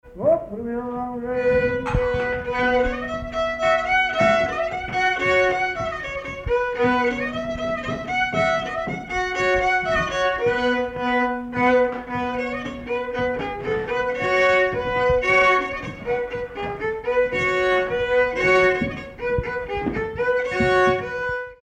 Villard-sur-Doron
circonstance : bal, dancerie
Pièce musicale inédite